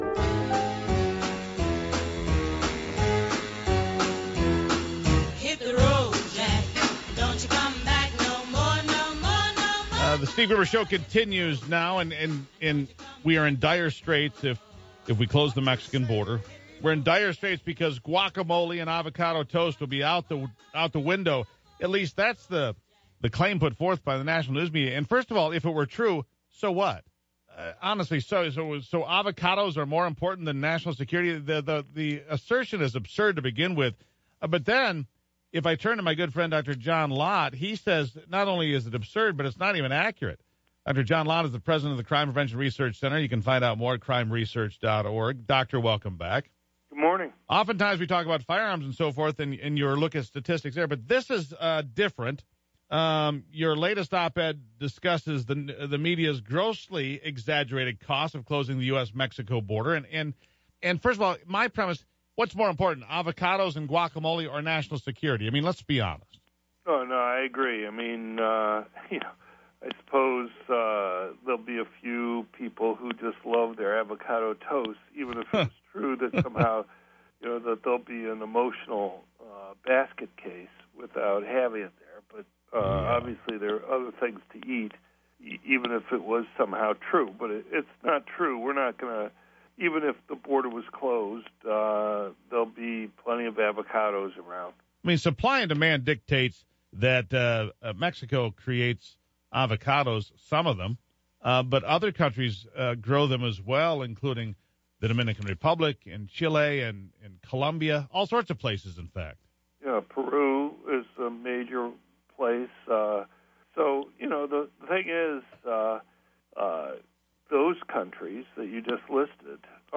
media appearance radio